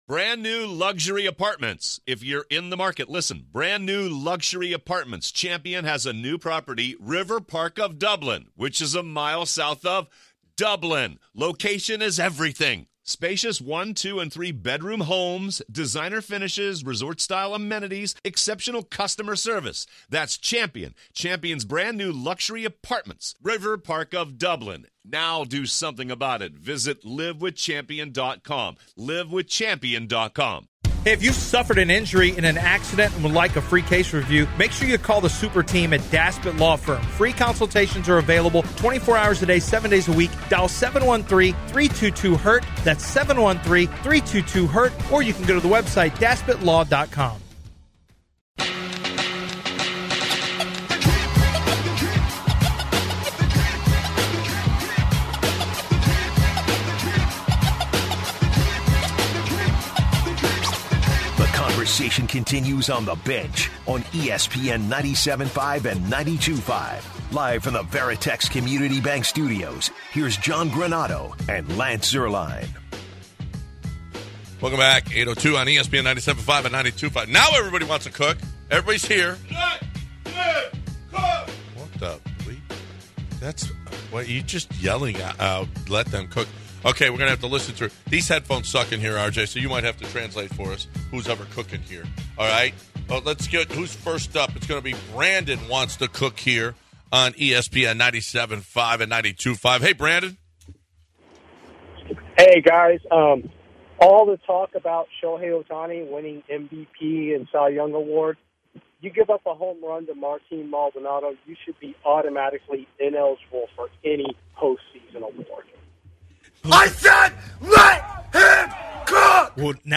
In our second hour, the guys extend the phone lines to the listeners to give their hottest takes on a let them cook Wednesday. Also, which NFL Teams are the most boring in the league?